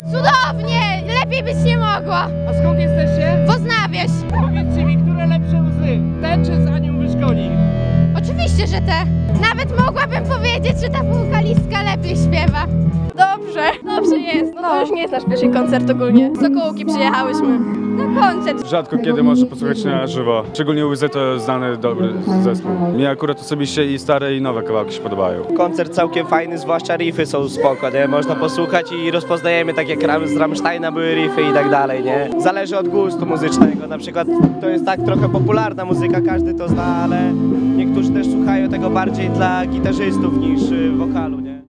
Na nudę nie narzekali mieszkańcy i turyści.